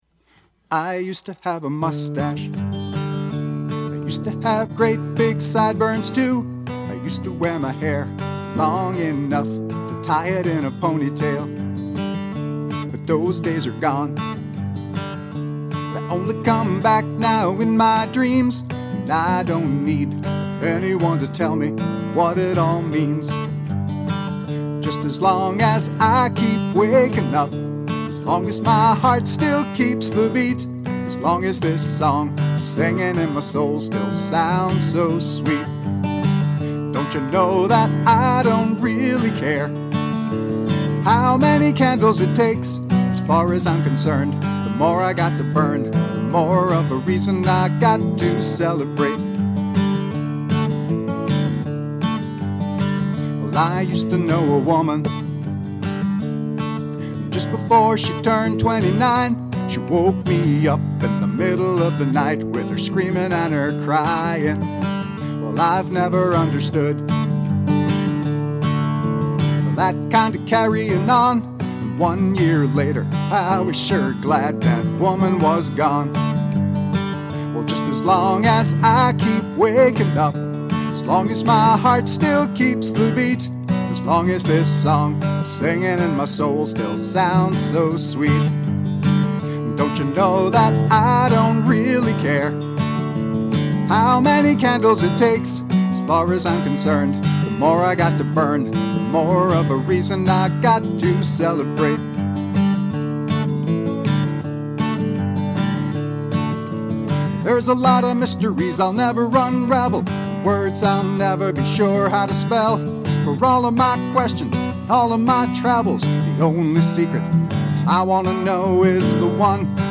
“Candles” words & music, guitar & vocals by